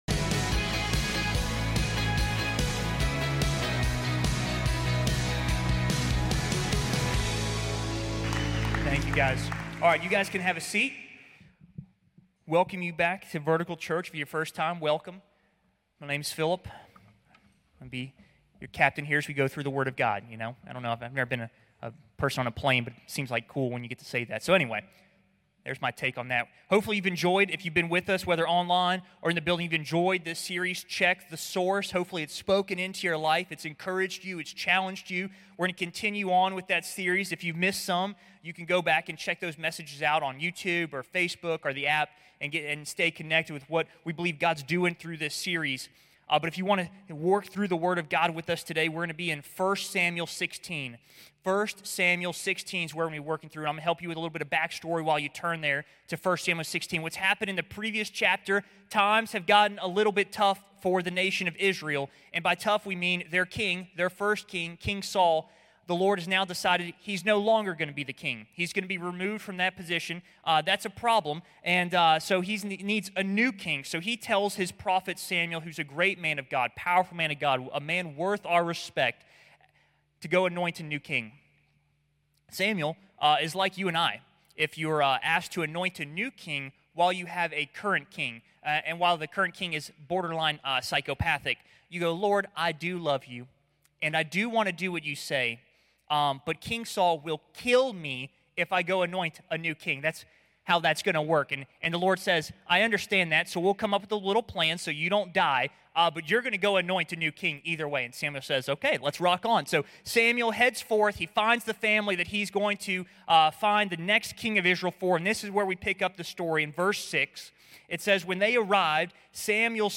However, we can find internal peace in Jesus. This sermon unpacks the tension from our "external enemies."